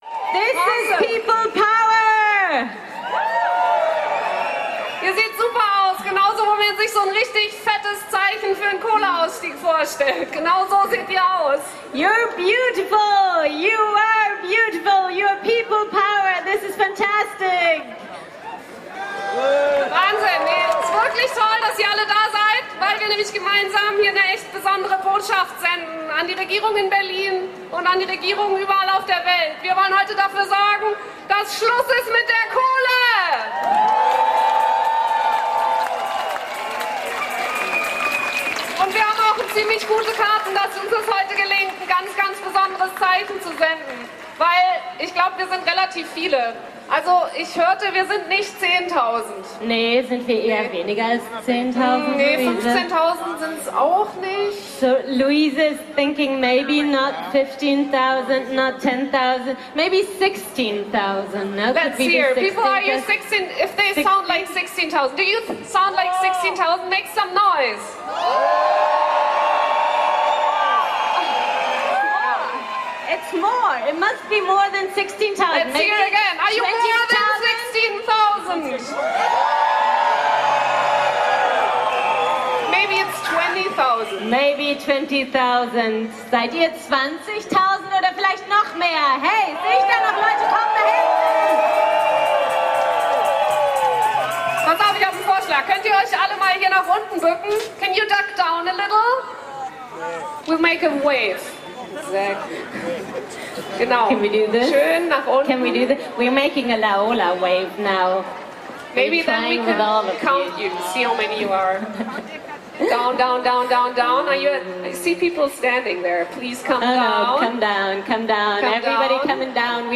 Kapitel 3: Abschlußkundgebung
Höhepunkt und Ende der Demonstration fand an der „Genscherallee“ mit zahlreichen Informationsständen und der Abschlußkundgebung statt, mit einigen Ansprachen und dem Auftritt von „Hop Stop Banda“.[20, 21, 22, 23]